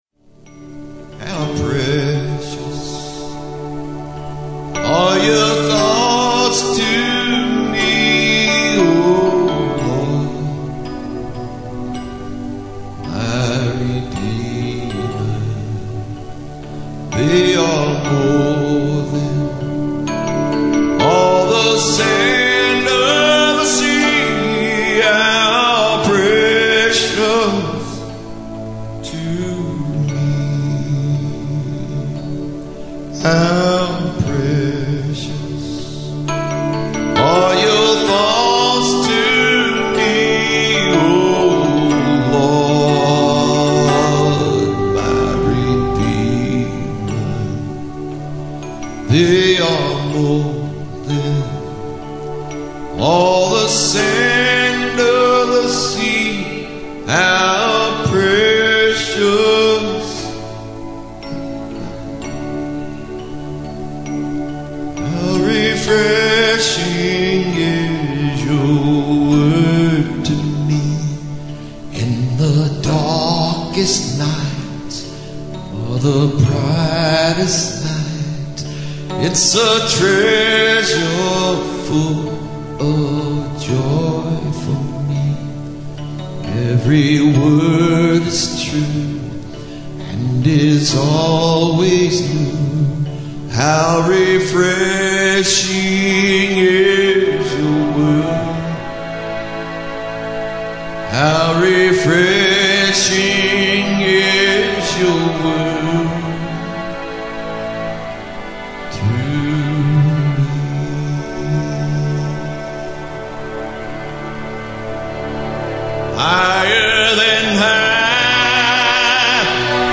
LISTEN (Medley)